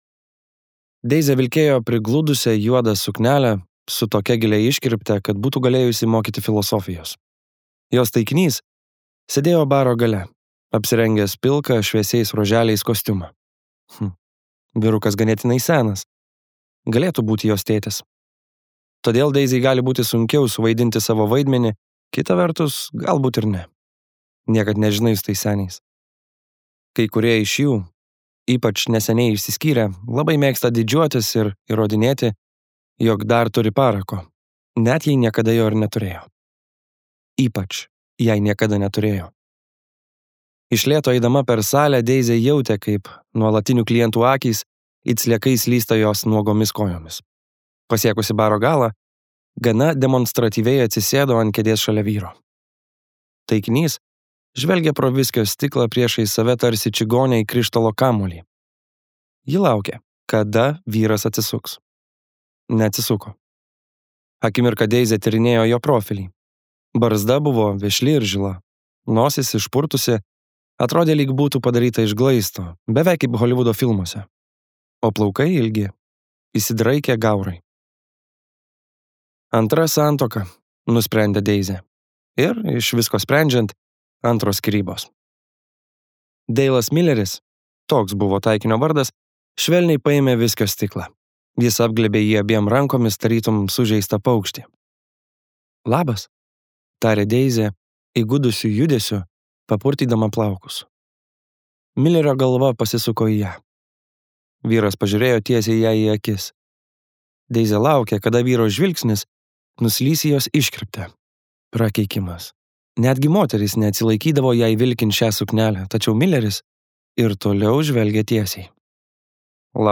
Harlan Coben audioknyga „Nepaleisk“ – neprilygstamos įtampos ir emocinių įžvalgų kupinas trileris, kuris kiekvieno skaitytojo dėmesį laikys prikaustęs iki paskutinio puslapio.